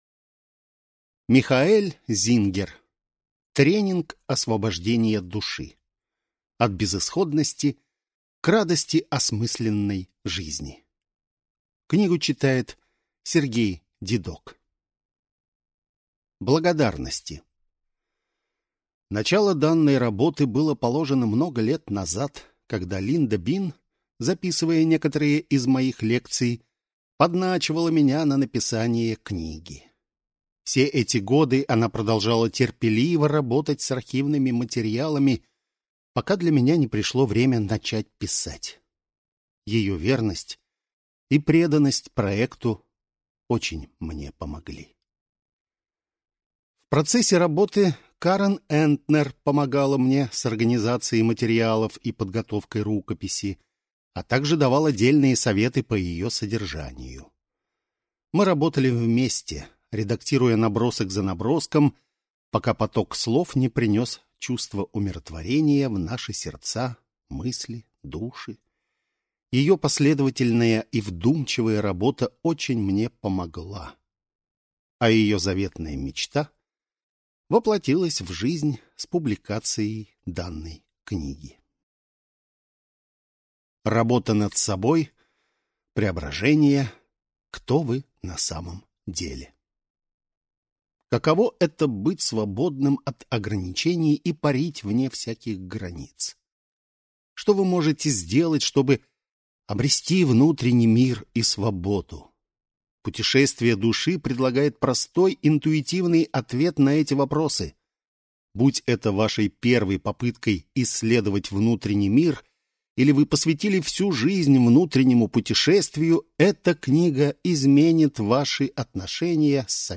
Аудиокнига Тренинг освобождения души. От безысходности к радости осмысленной жизни | Библиотека аудиокниг